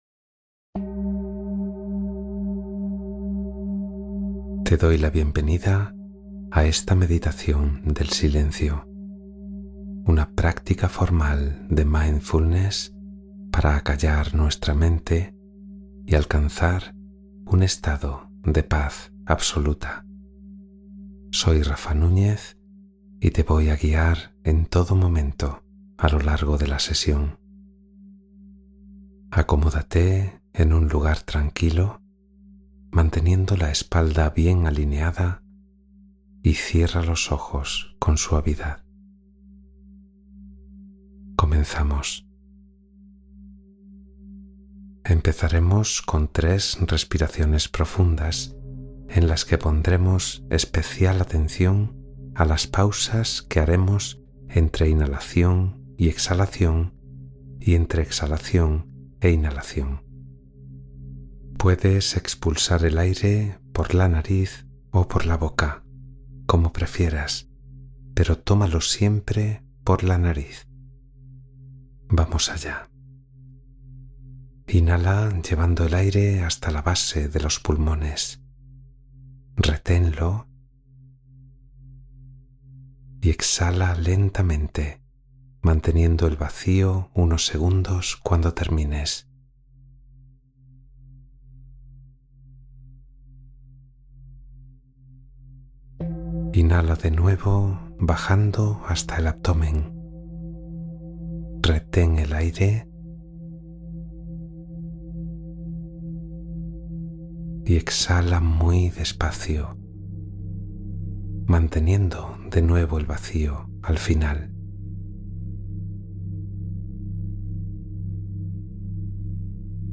Respiraciones Guiadas para Calmar la Mente